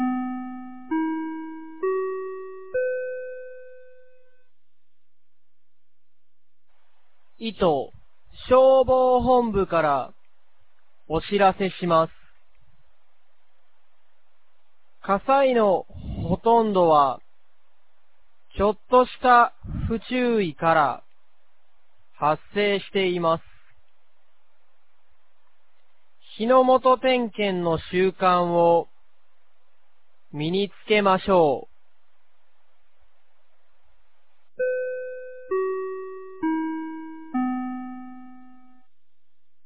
2025年01月06日 10時01分に、九度山町より全地区へ放送がありました。
放送音声